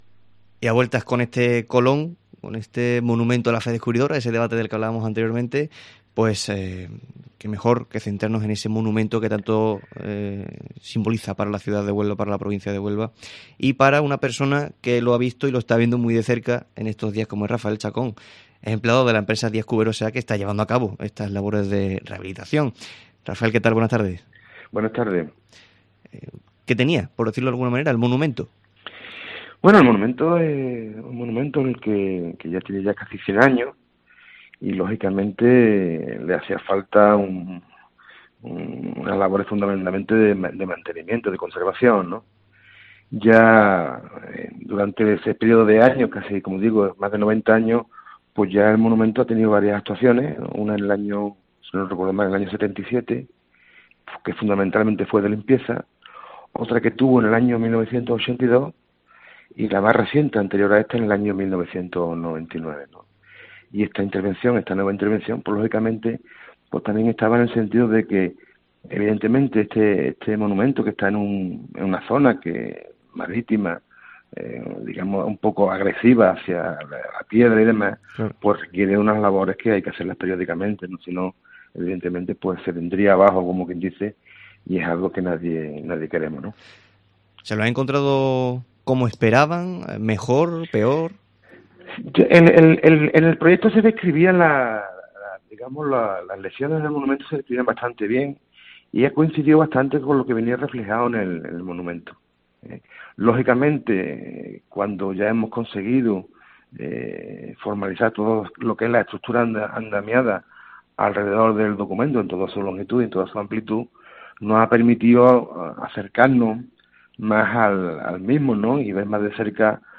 Hemos charlado en el Mediodía COPE Huelva de este viernes